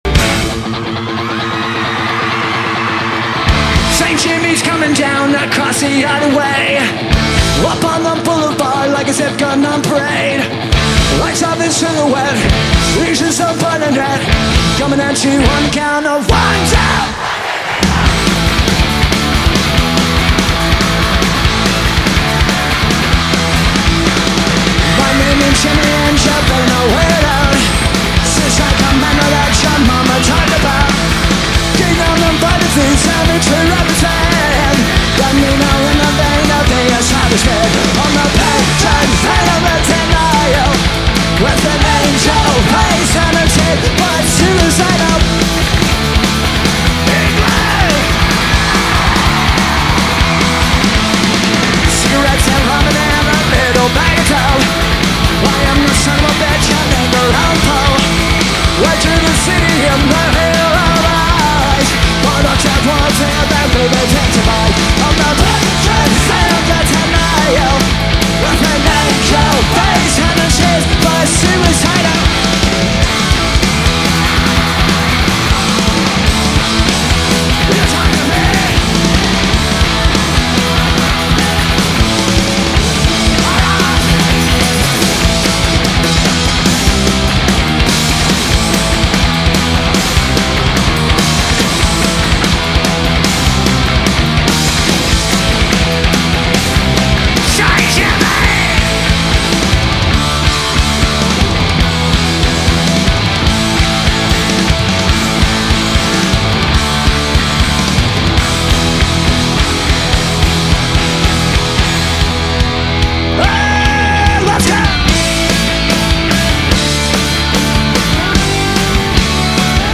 live from Milton Keynes